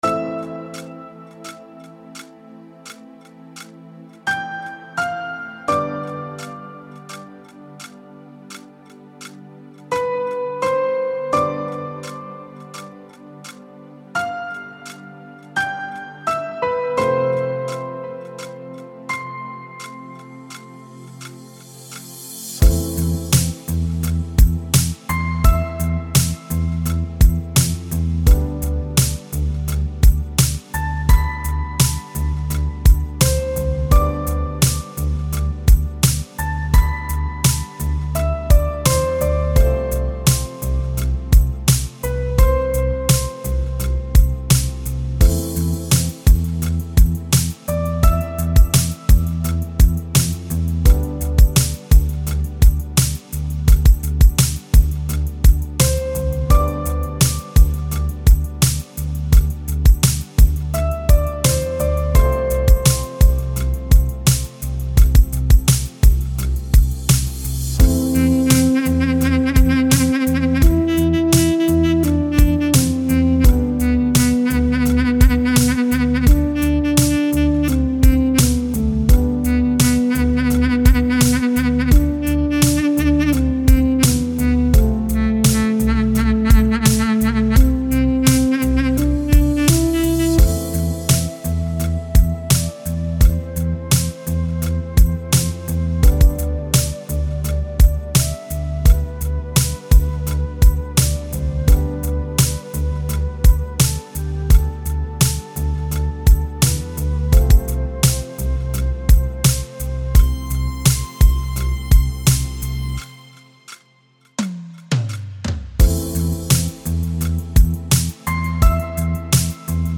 Узбекская музыка
Минусовка